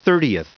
Prononciation du mot thirtieth en anglais (fichier audio)